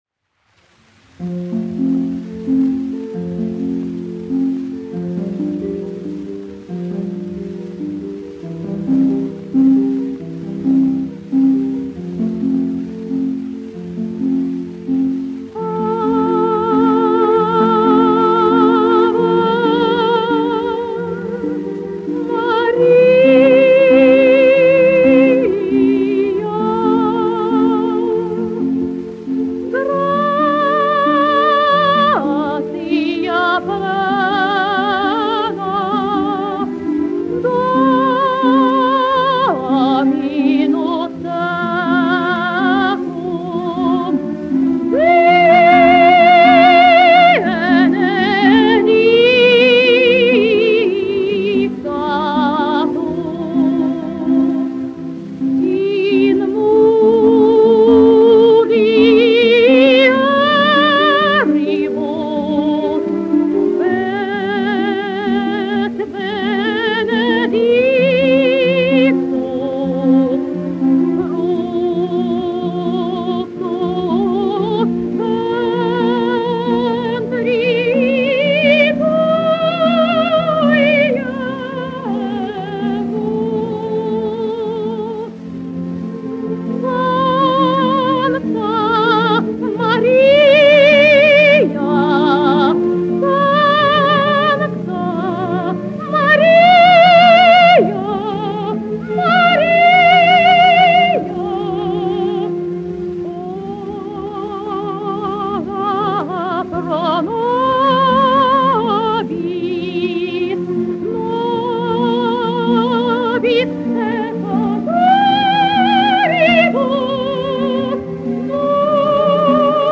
1 skpl. : analogs, 78 apgr/min, mono ; 25 cm
Garīgās dziesmas
Dziesmas (augsta balss) ar orķestri
Latvijas vēsturiskie šellaka skaņuplašu ieraksti (Kolekcija)